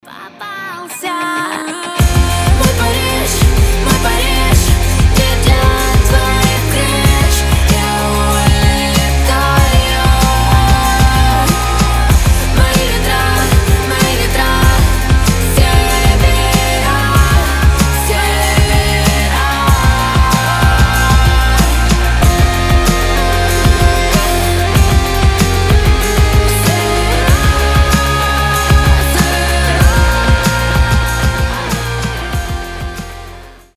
• Качество: 320, Stereo
громкие
атмосферные
женский голос
Alternative Rock